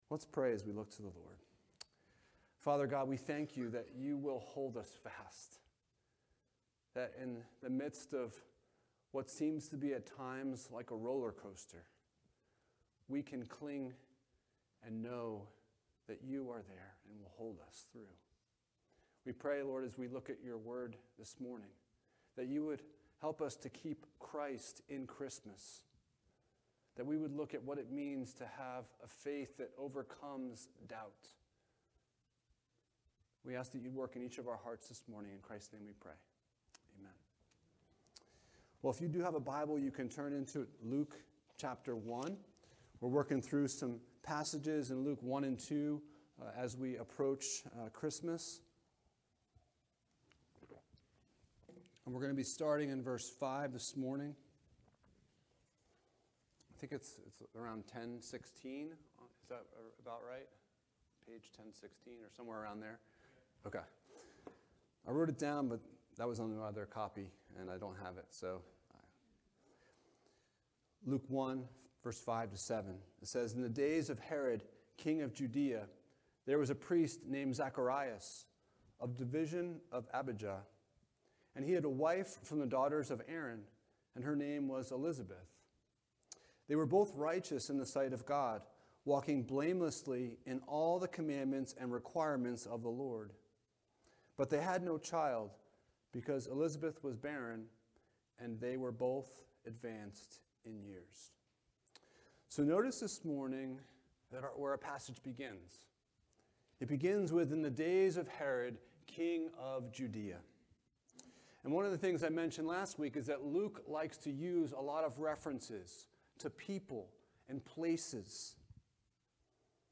5-25 Service Type: Sunday Morning « What is Your Source of Truth?